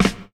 eT_DOUB_SNR.wav